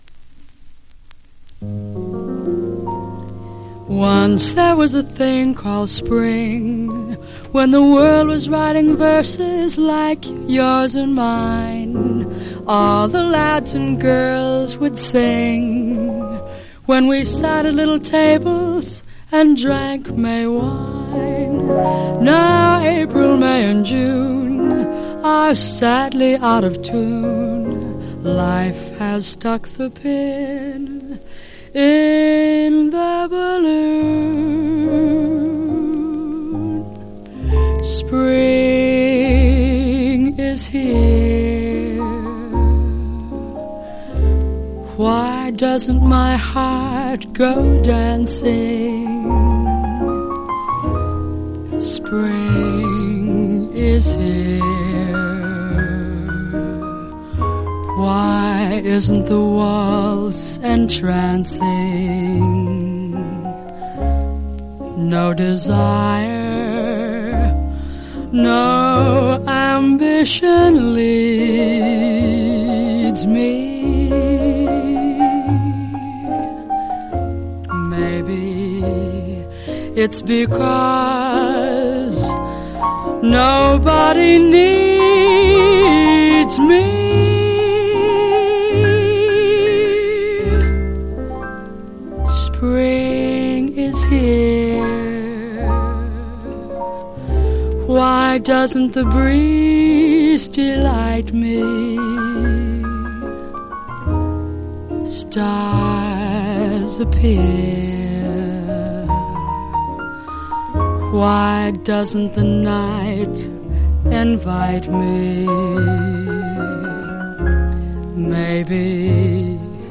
（プレス・小傷によりチリ、プチ音ある曲あり）※曲名をクリッ…